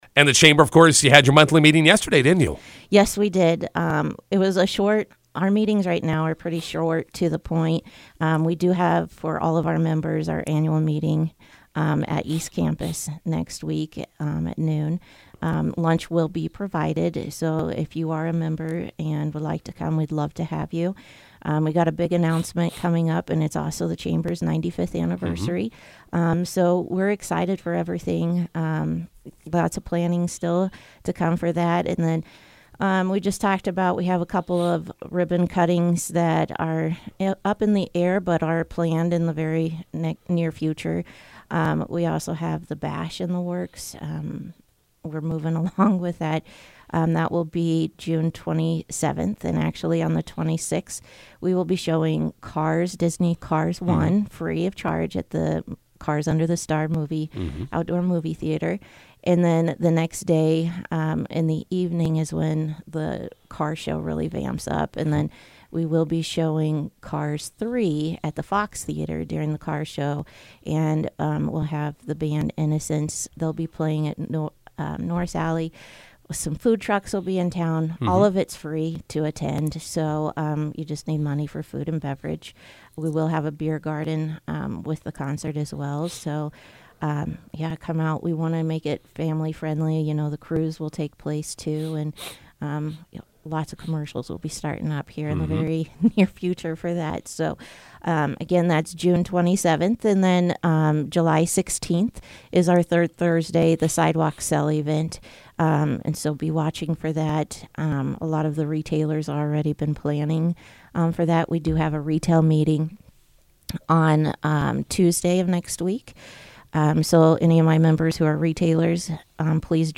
INTERVIEW: McCook Chamber of Commerce April meeting recap